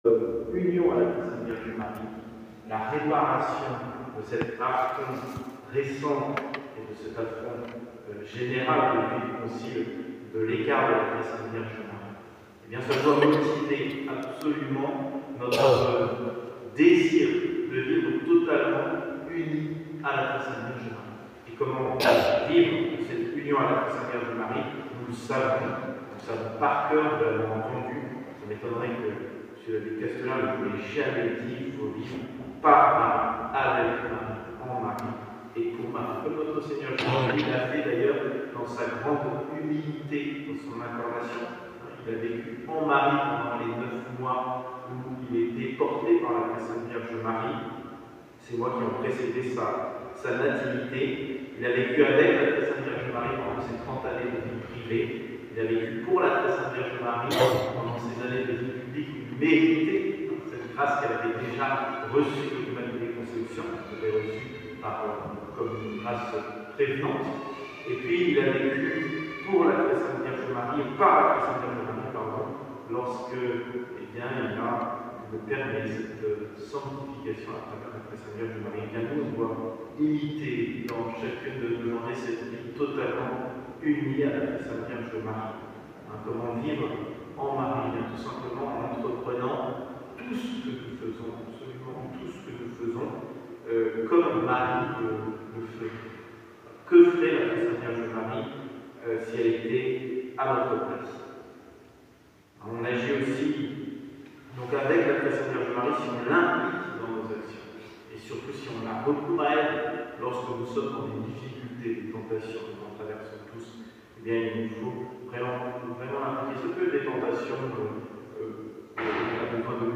Nous n’avons pu enregistrer qu’une partie du sermon pendant la Sainte Messe.